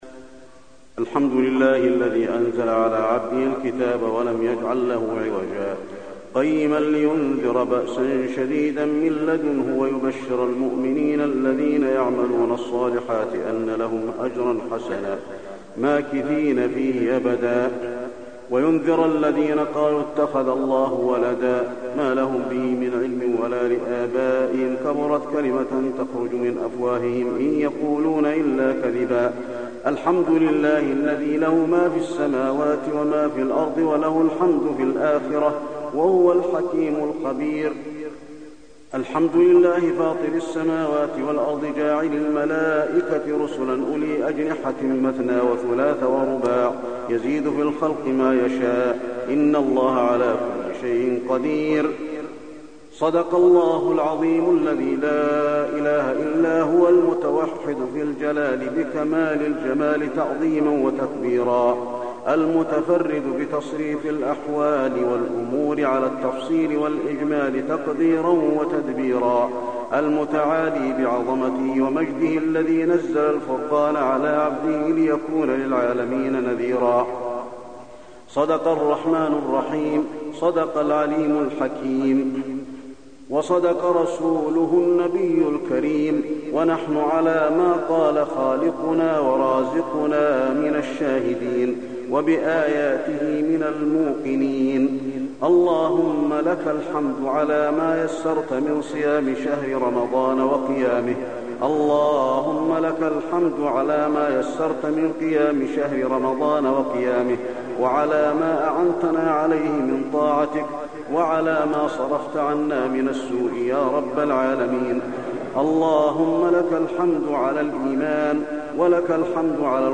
دعاء ختم القرآن
المكان: المسجد النبوي دعاء ختم القرآن